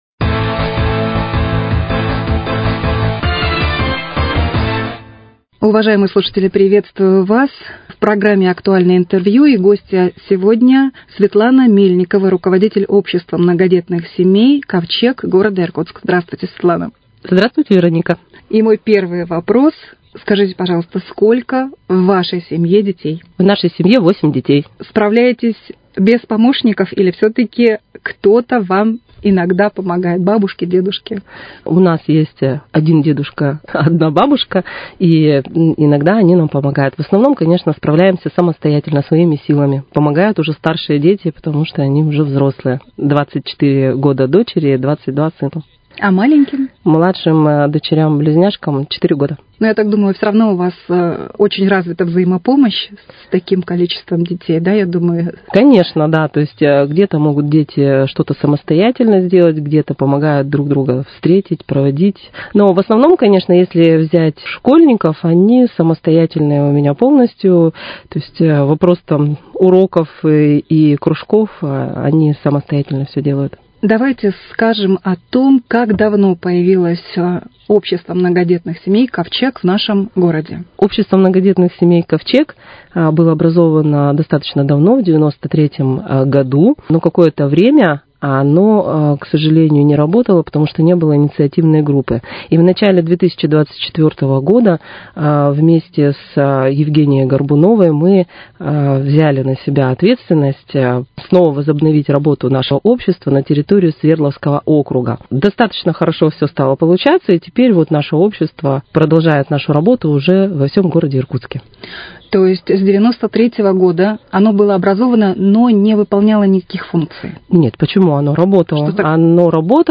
Актуальное интервью: Беседа